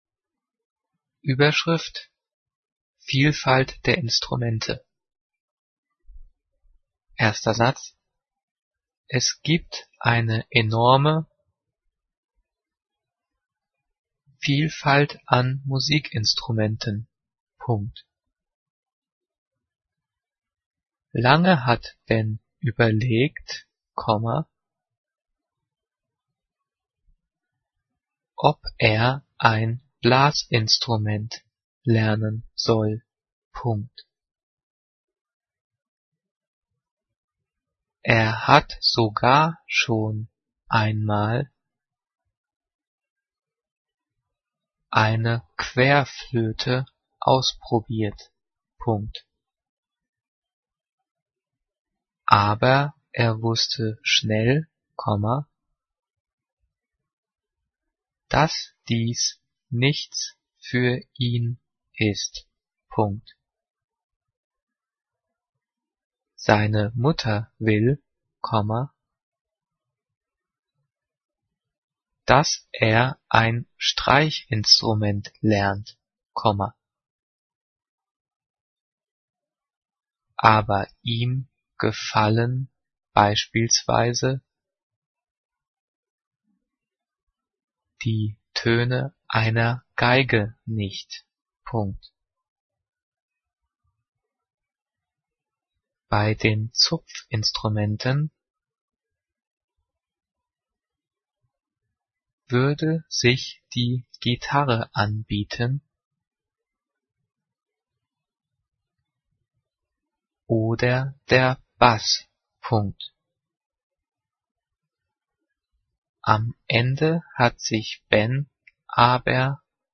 Die vielen Sprechpausen sind dafür da, dass du die Audio-Datei pausierst, um mitzukommen.
Übrigens, die Satzzeichen werden außer beim Thema "Zeichensetzung" und den Übungsdiktaten der 9./10. Klasse mitdiktiert.
Diktiert: